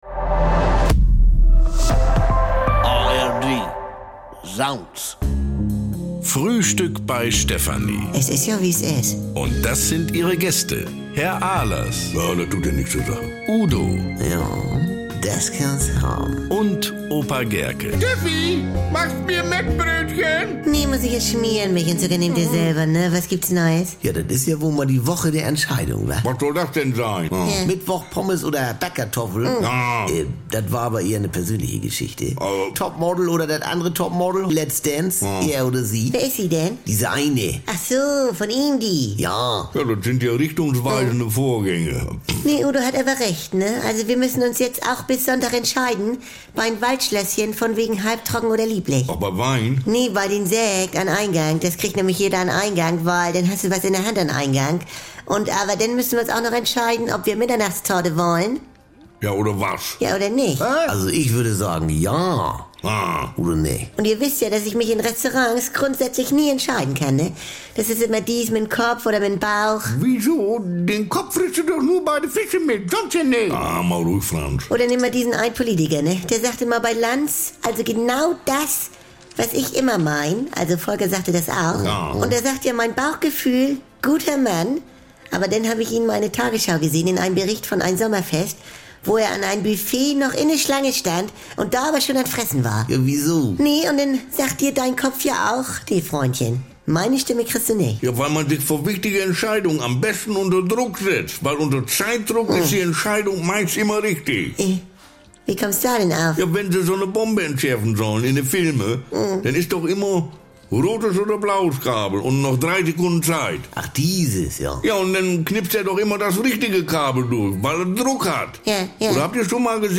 und brummeliges Gemecker bekommt ihr jeden Tag im Radio oder